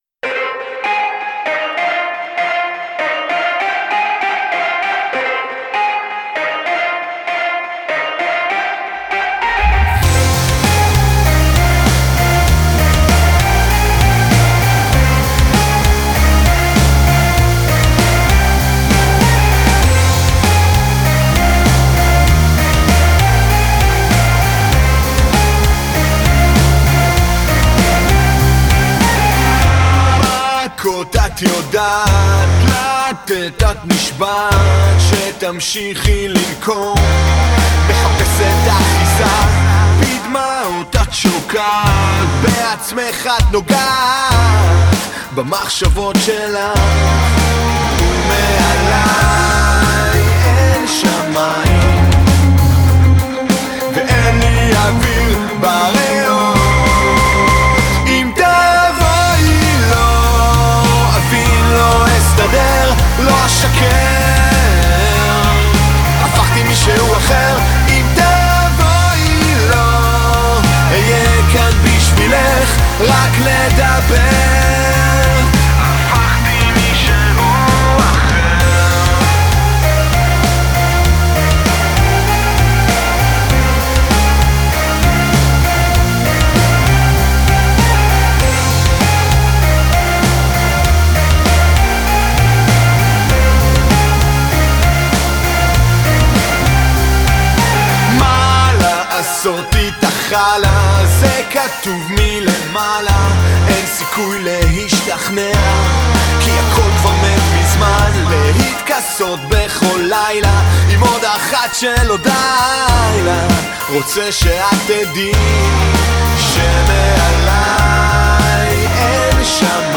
רוק ישראלי